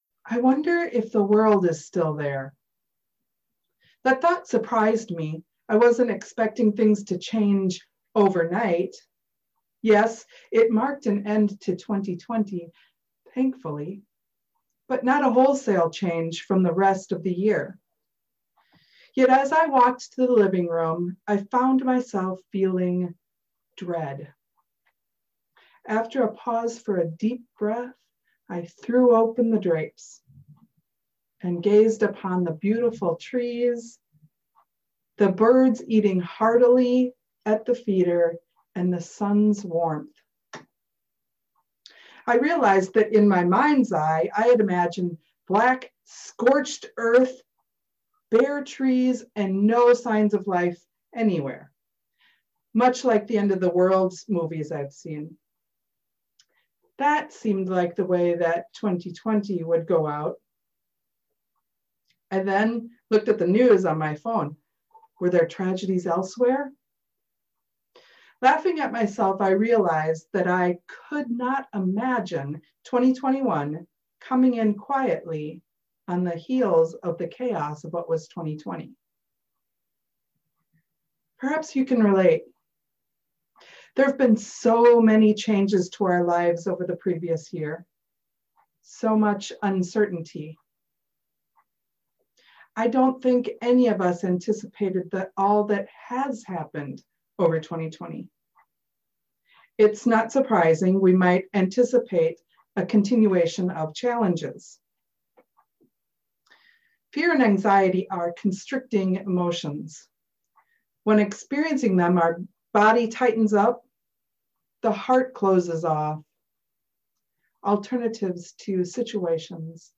This sermon explores the transformative power of the imagination as a tool for overcoming fear, anxiety, and social stagnation.